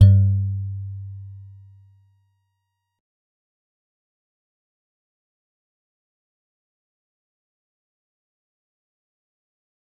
G_Musicbox-G2-mf.wav